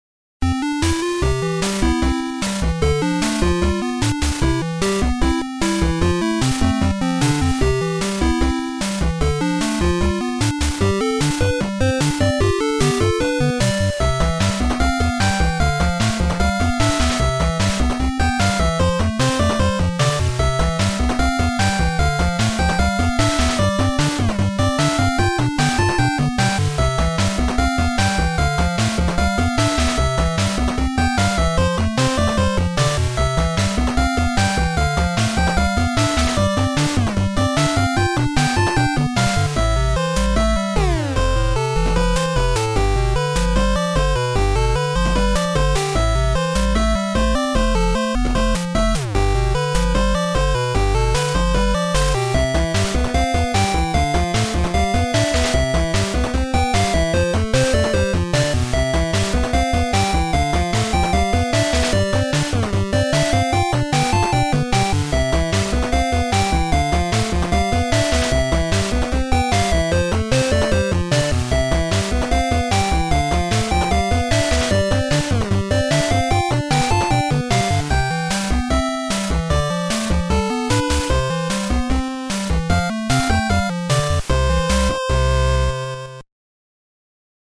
A brave and upbeat chiptune song.
More JRPG chiptune music! This time, a brave song called "There's No Stopping Us!"